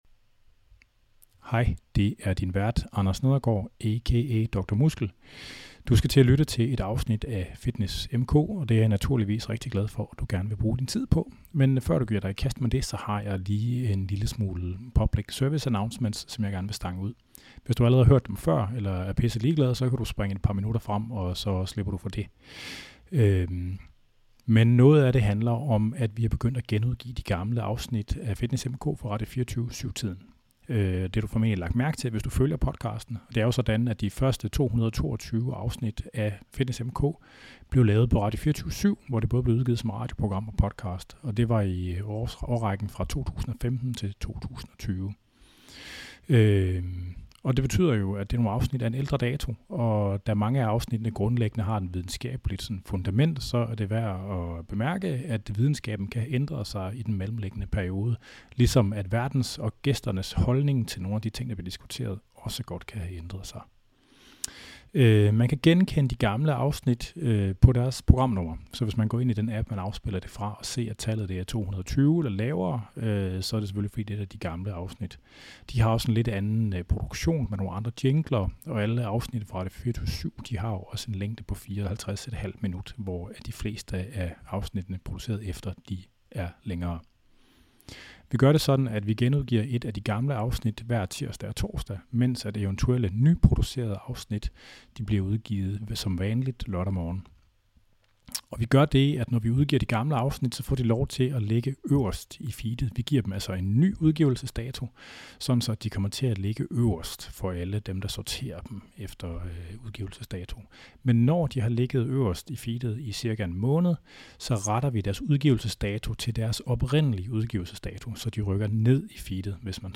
Fitness M/K